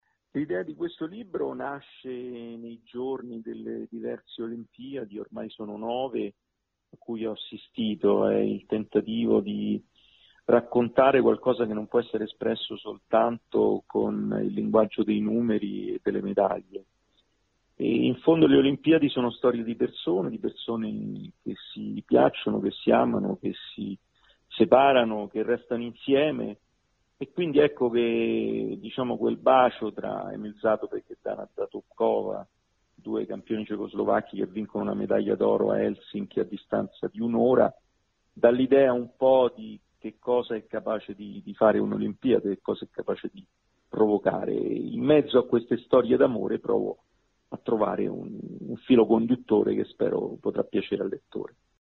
Baseball in carrozzina, all’Istituto di Montecatone arriva una nuova disciplina. Il servizio